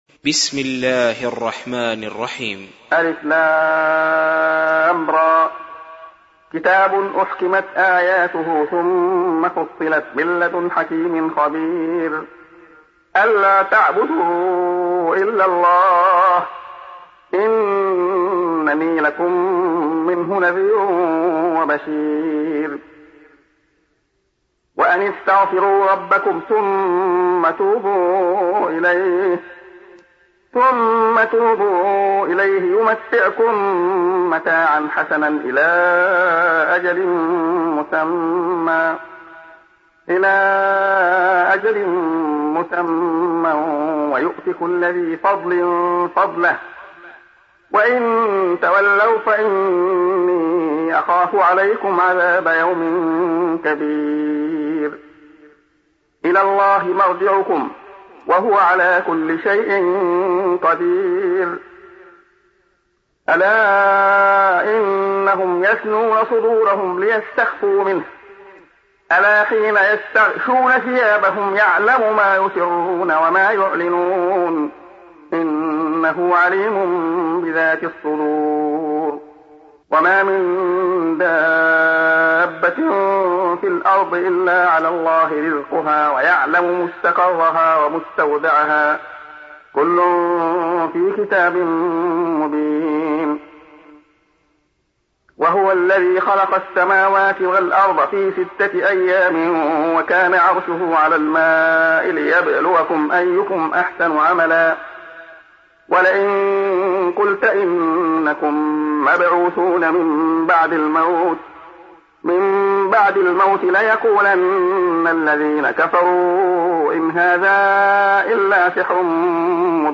سُورَةُ هُودٍ بصوت الشيخ عبدالله الخياط